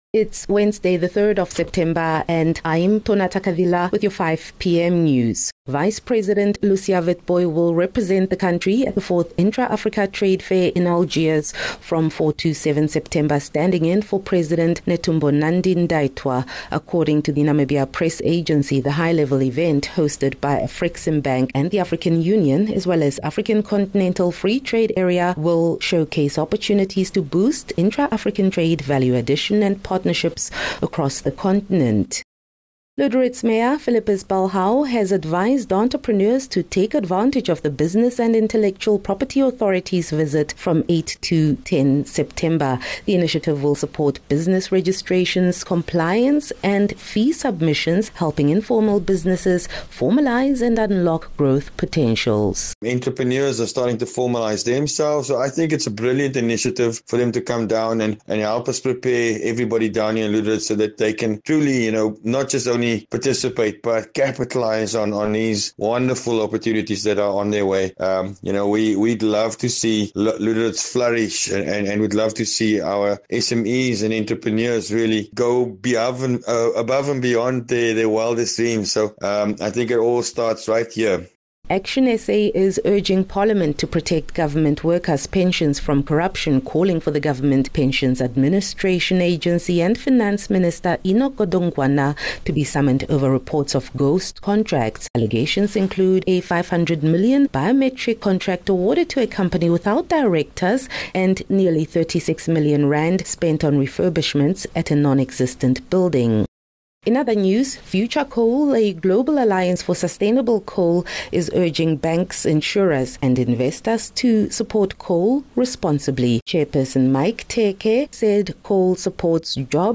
3 Sep 3 September - 5 pm news